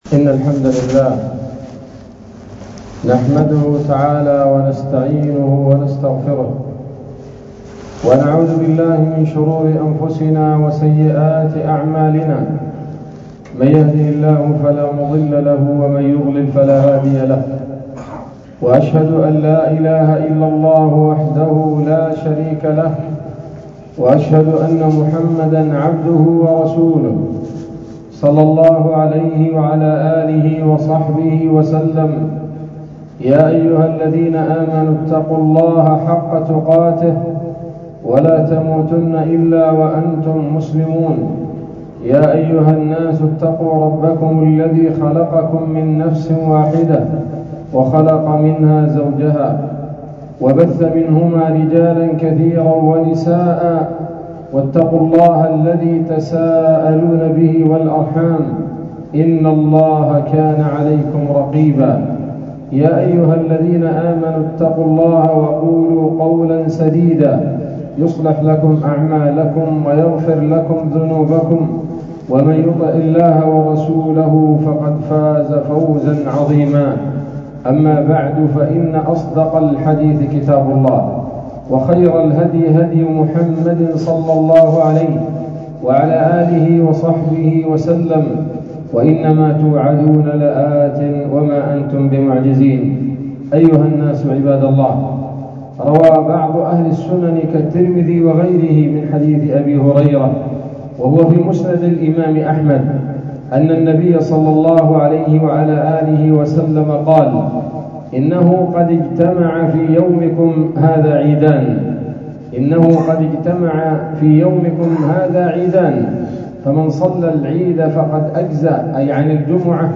خطبة جمعة بعنوان: (( دروس من صلاة الجمعة )) 10 ذو الحجة 1446 هـ، مسجد آل عمران شارع ناصر الثورة - الجيزة - مصر